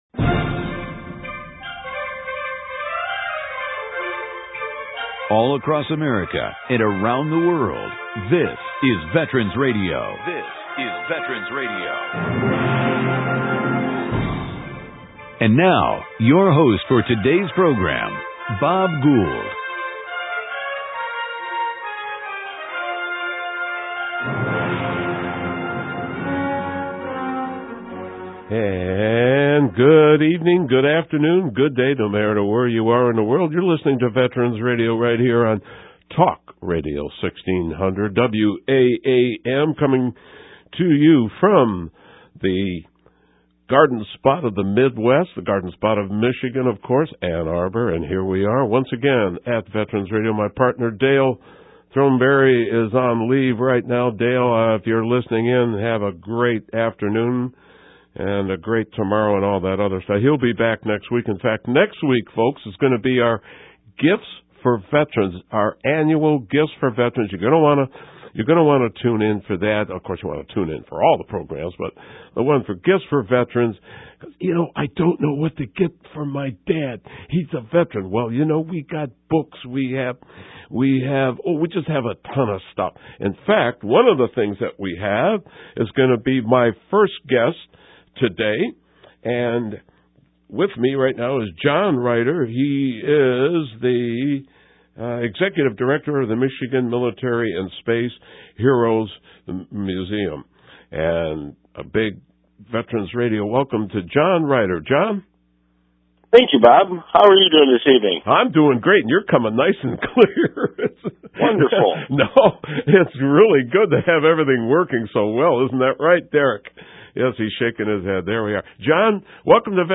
Then…They reached the Chosin reservoir and the Chinese swept across the border. Hear this gripping story from those who were there.